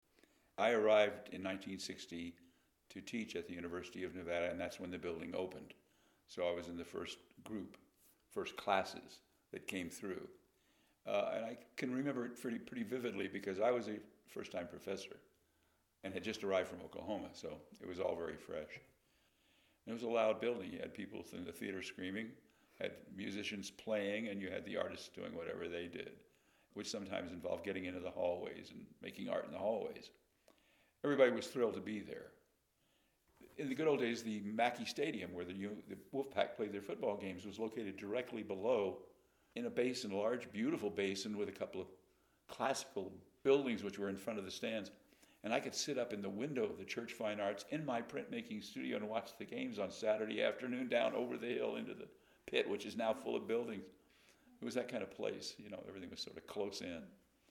Interviewed in 2015